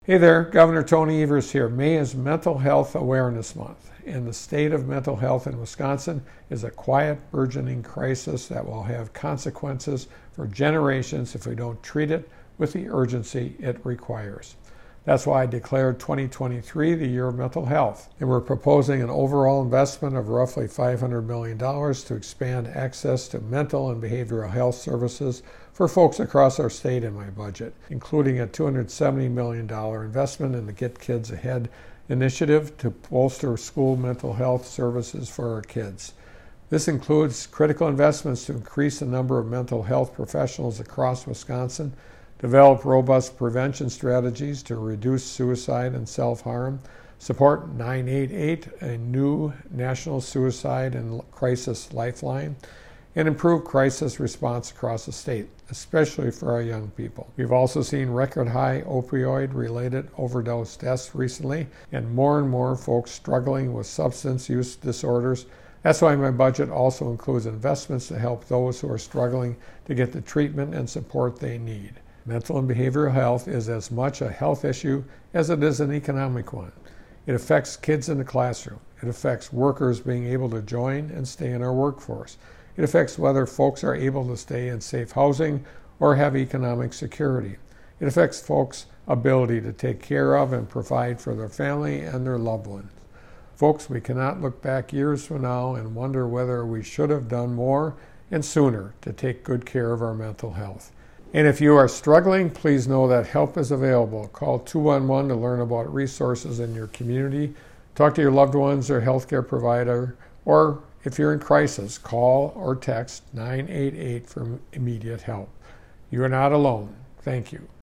Weekly Dem radio address: Gov. Evers on mental health - WisPolitics
MADISON — Gov. Tony Evers today delivered the Democratic Radio Address highlighting his plan to expand access to mental and behavioral healthcare across Wisconsin.